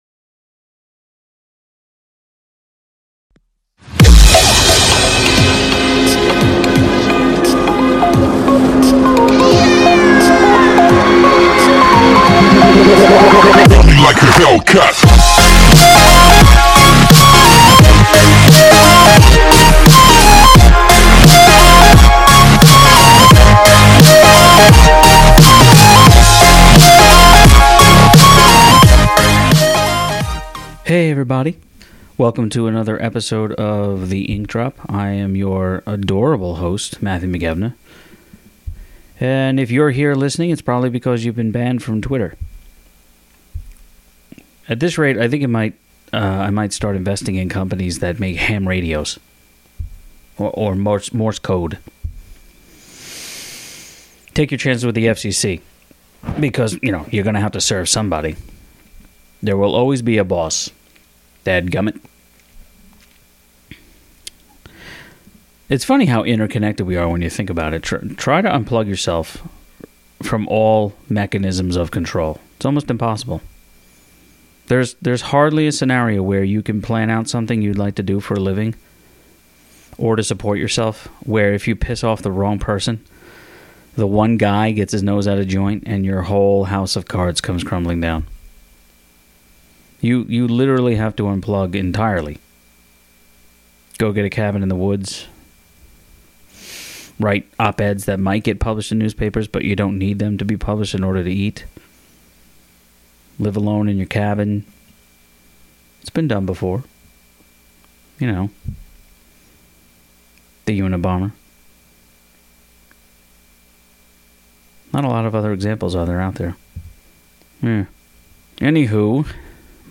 We actually held this conversation shortly before Christmas, but the conversation was about the state of journalism, both local and national.